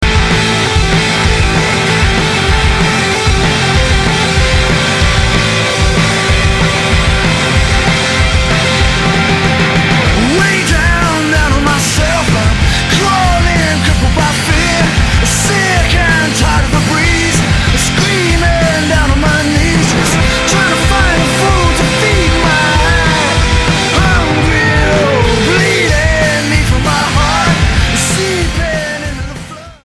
STYLE: Hard Music
strident rifforamic action
rasping vocals
powerful song